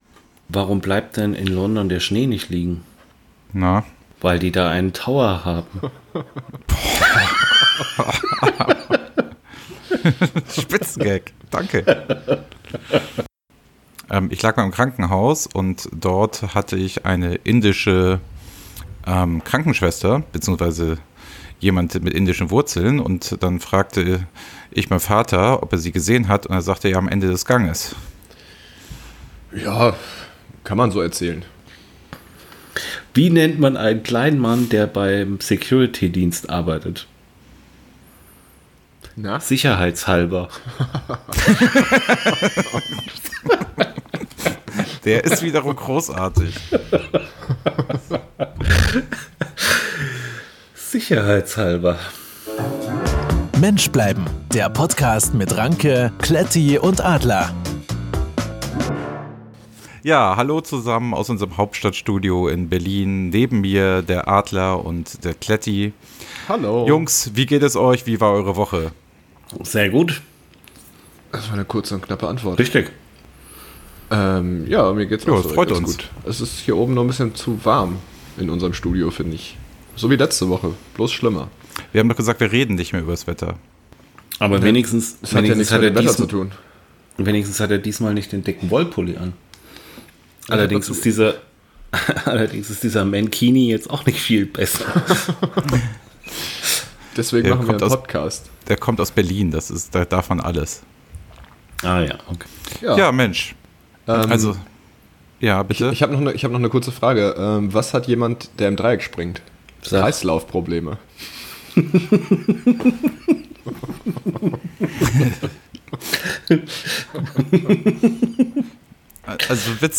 Zwischen vergessenen Verwandten, zahlreichen Konzerten, blieb auch dieses Mal Platz für Spaß und Gags auf allen Decks. Das Zusammenspiel der drei Protagonisten ist eine Symphonie für die Ohren.